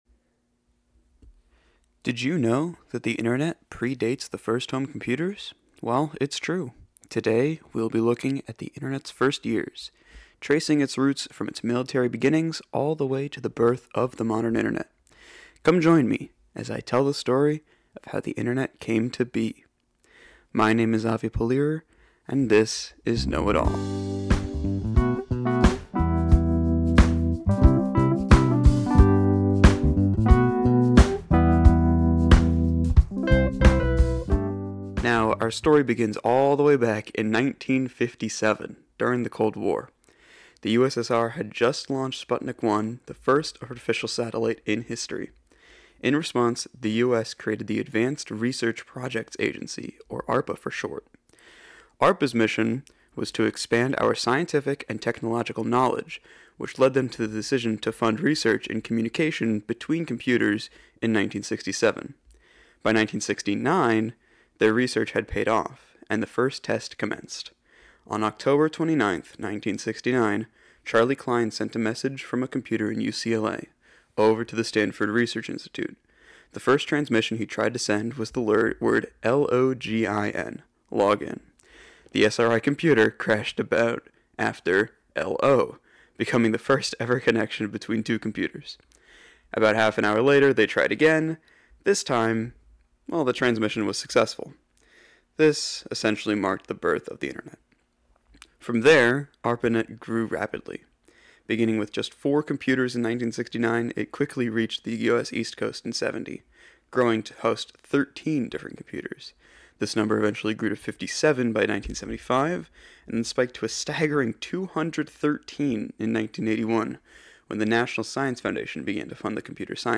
Hosted by: Students at the Community College of Baltimore County